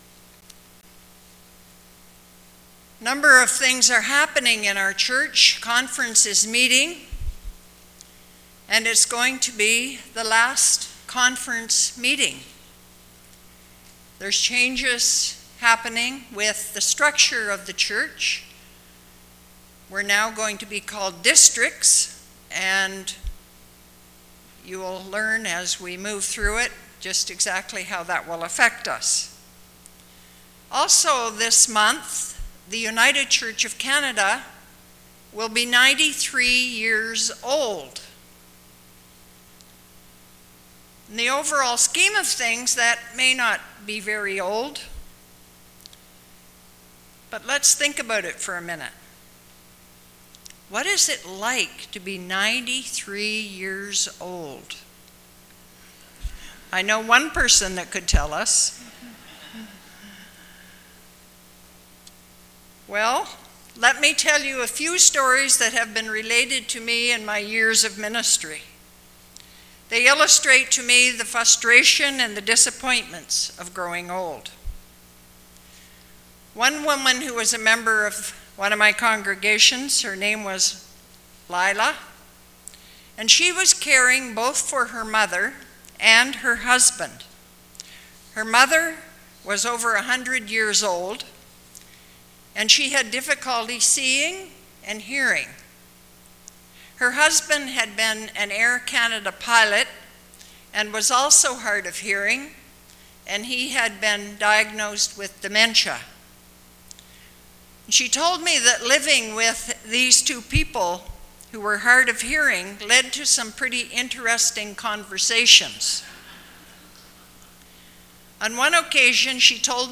Sermons | Northwood United Church